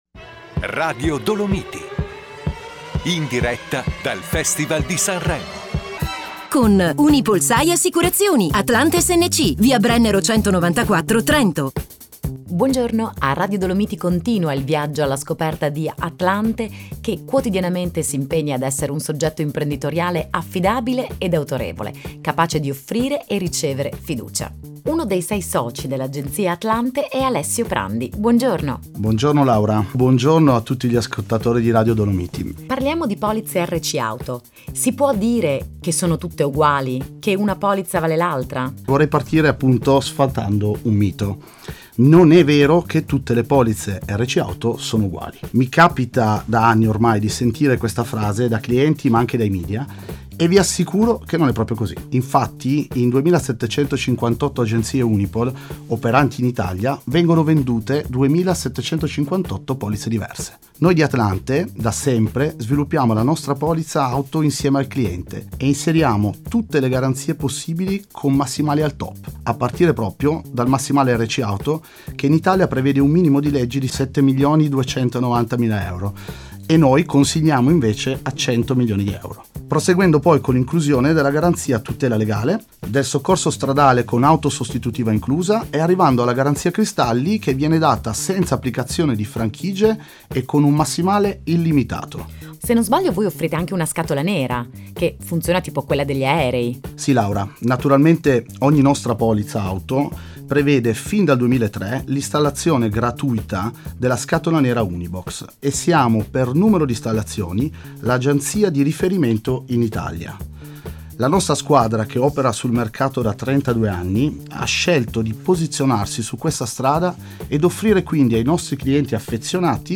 Ad inizio febbraio i nostri consulenti sono stati ospiti di Radio Dolomiti.